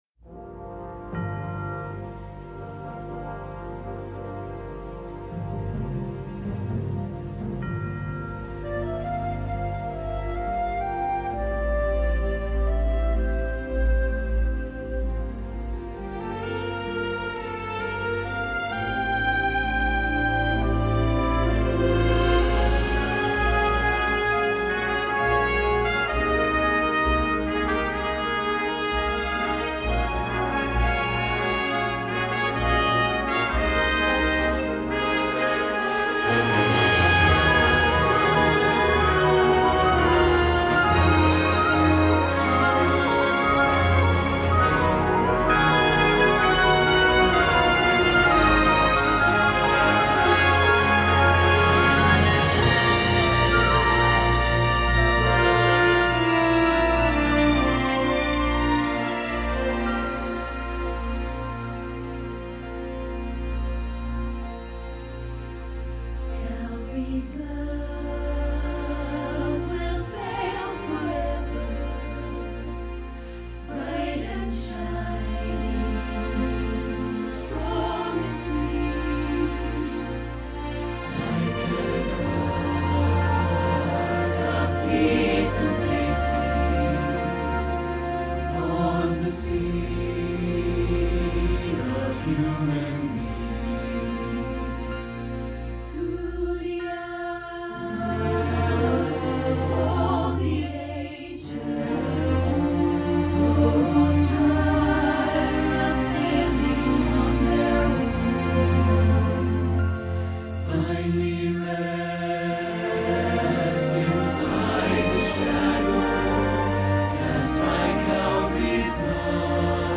Anthem: Calvary’s Love-Split